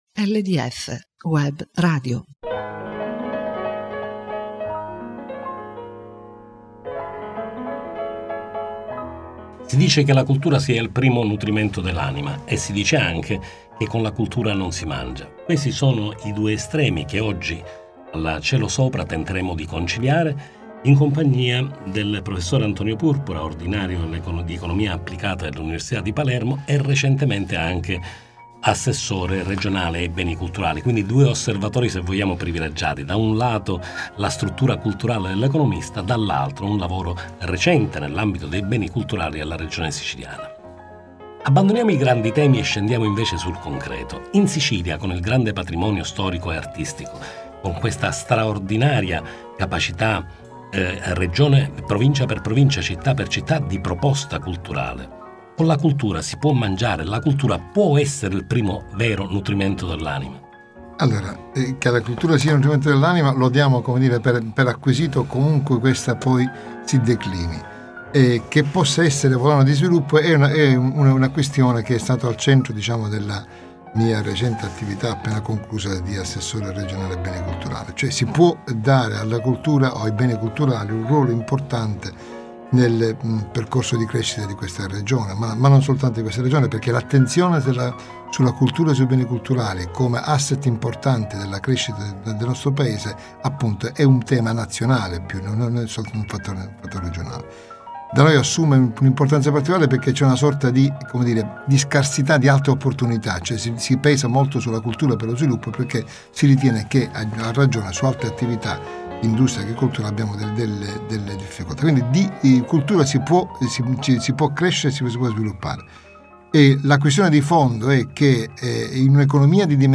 Intervista sentimentale a Antonio Purpura
Programma webRadio